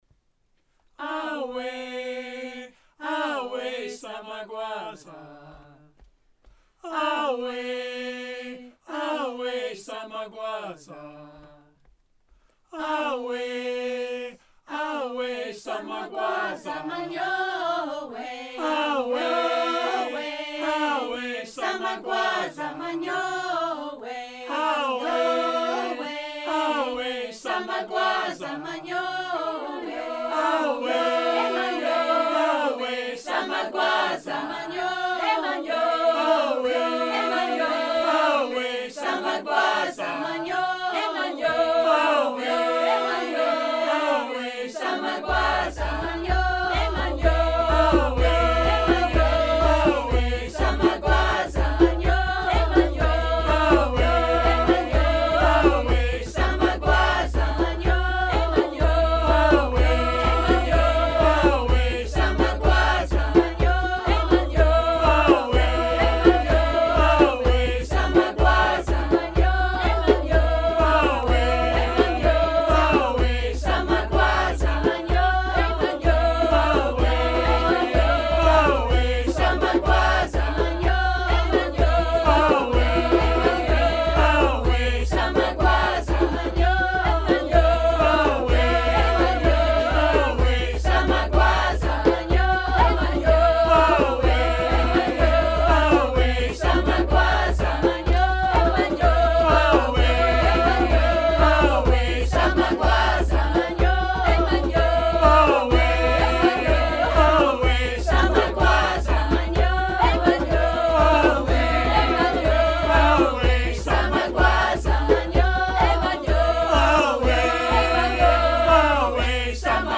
(South Africa, Xhosa Initiation Rite Song)